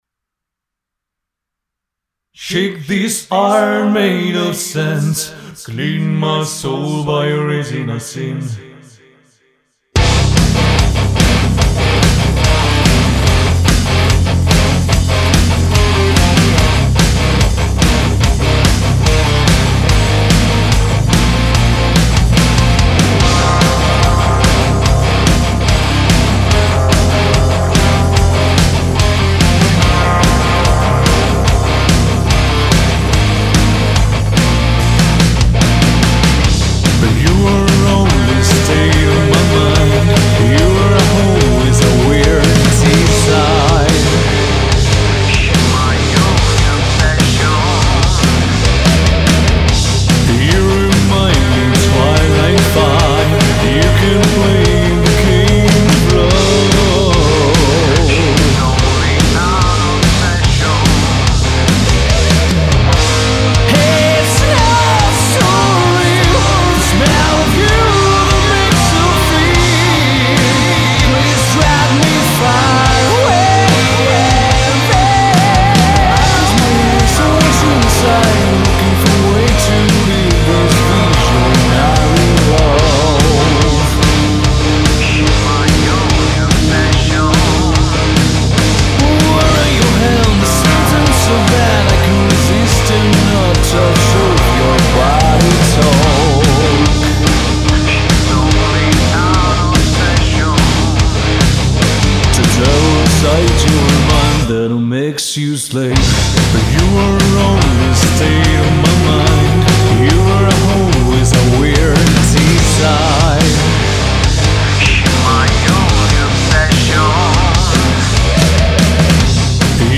Представяме Ви нашите приятели от Италия готик групата
Dark & Gothic metal band